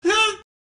Play, download and share Yay! Reaction original sound button!!!!